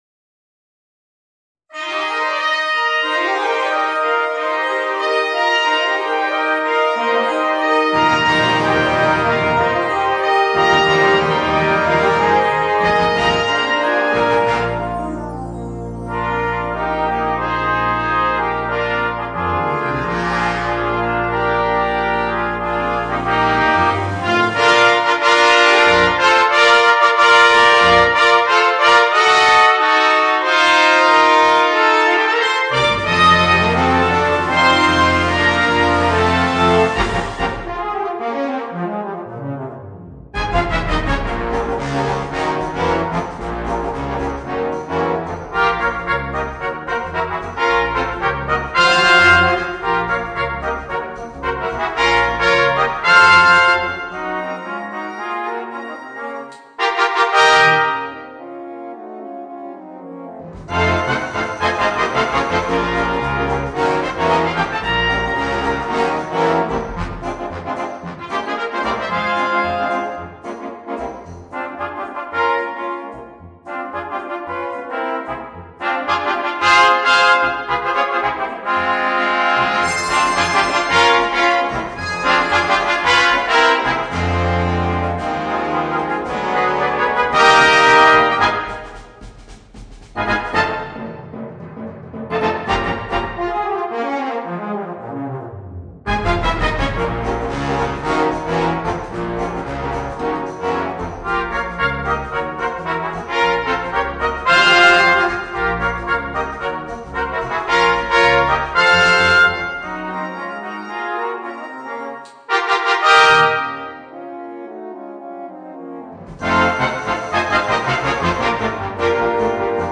Voicing: 3 Cornets and Brass Band